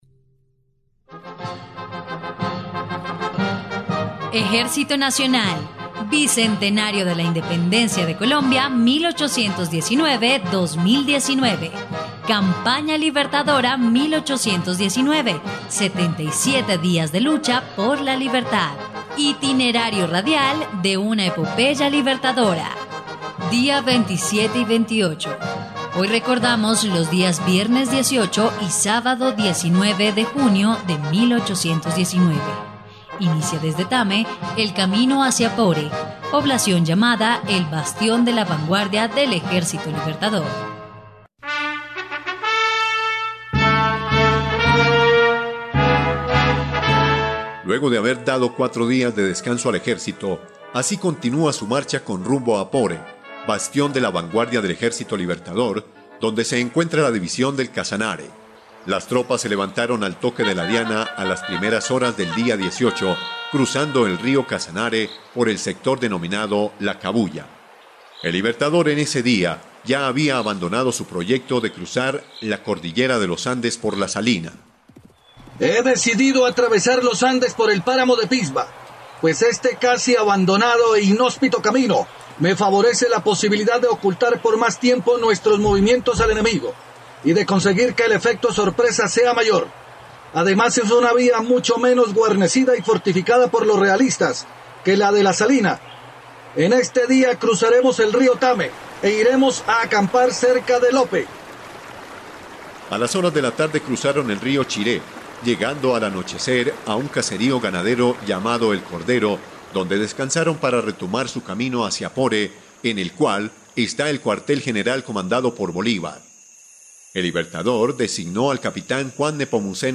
dia_27_y_28_radionovela_campana_libertadora.mp3